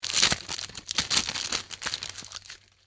inv_medkit.ogg